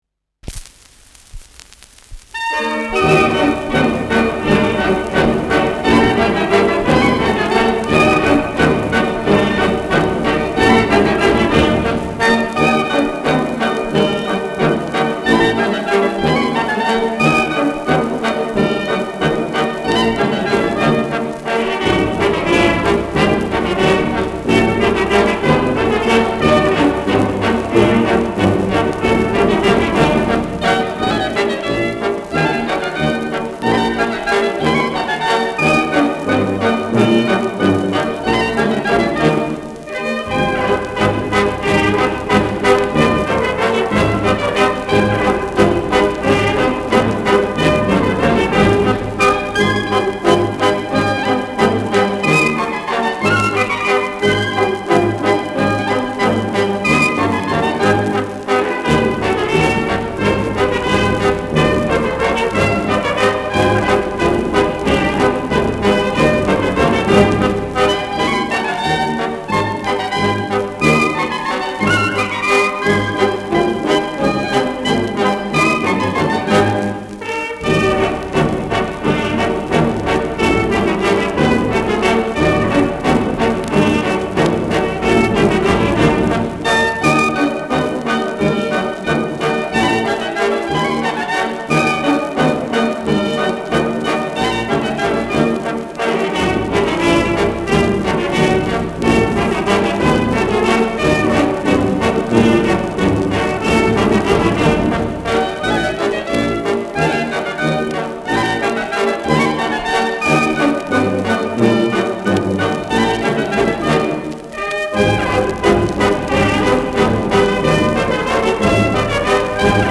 Schellackplatte
leichtes Knistern
Kapelle Hallertau (Interpretation)
[Vohburg an der Donau] (Aufnahmeort)